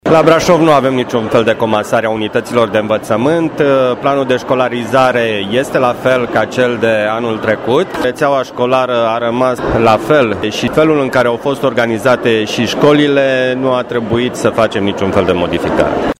Astfel, inspectorul general al ISJ Brașov, Ion Negrilă, a risipit orice temere, care putea să-și facă loc în rândul părinților și elevilor, după anunțul intenției fostului ministru al Educației: